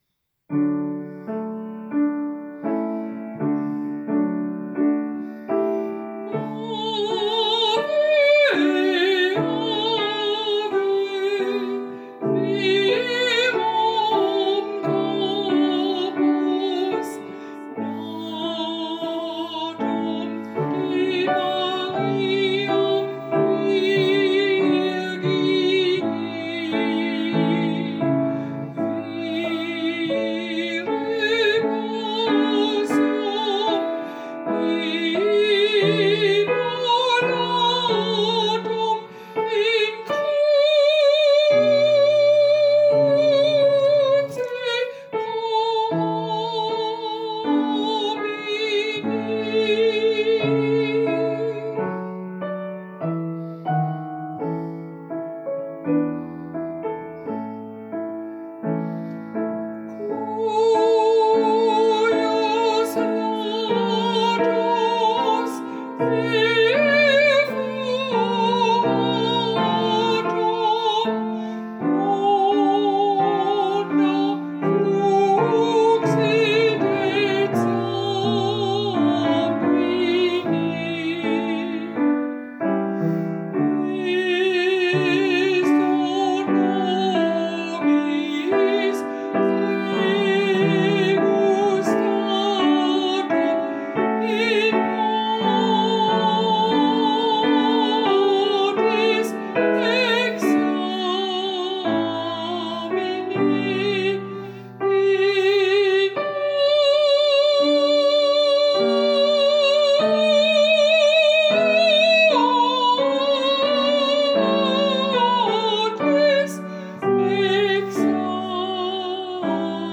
Ave verum corpus, Mozart Gesang und Klavier